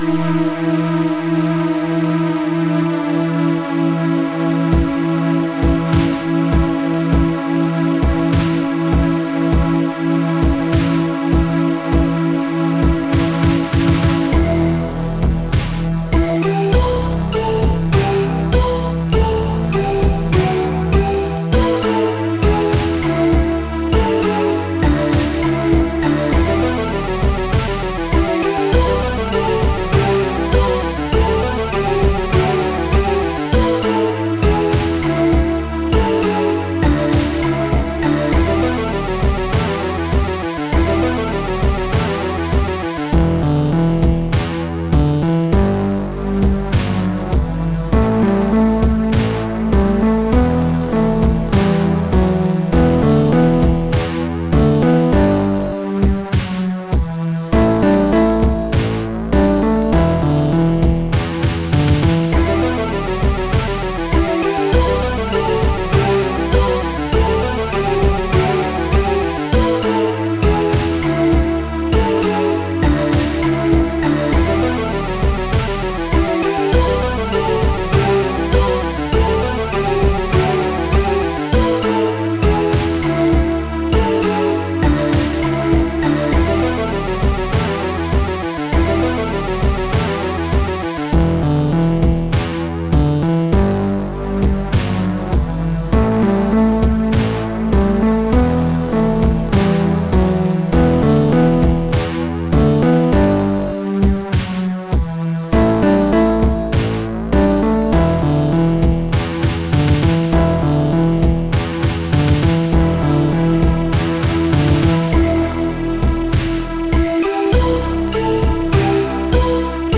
Features digitized title soundtrack at 10.1KHz
Music written with Quartet